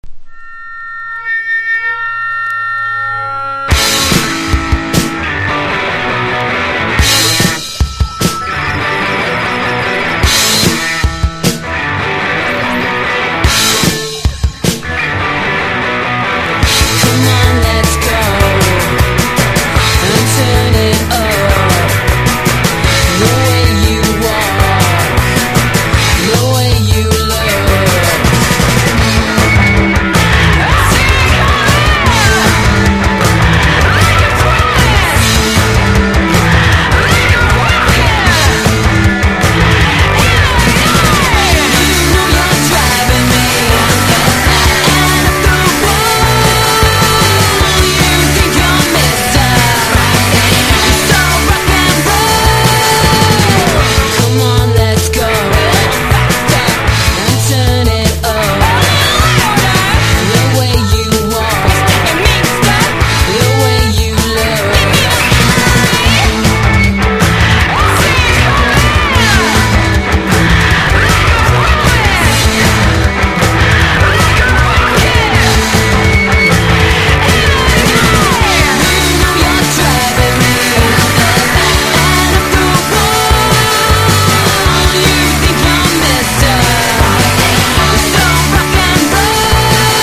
INDIE DANCE
イングランド出身4人組ガールズ・パンク・ロックバンド